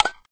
clack_can_opening.ogg